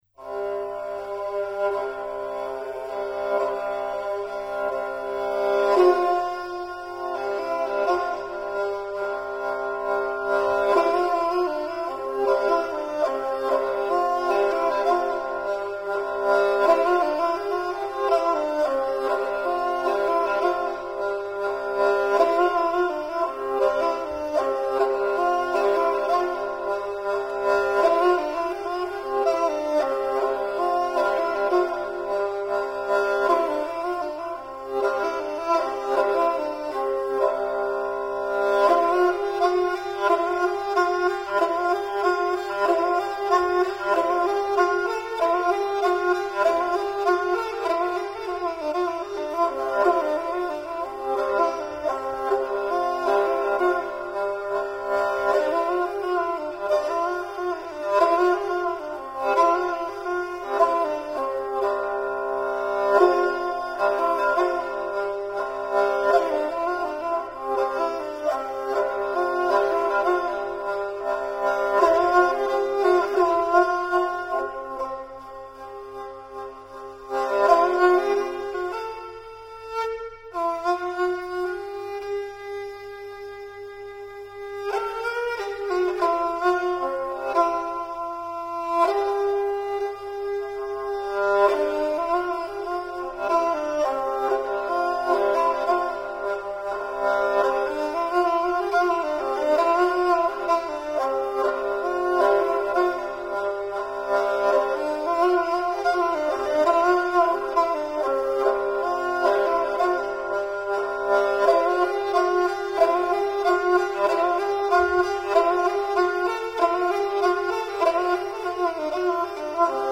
На аудиозаписи эта самая киргизская скрипка "кыяк".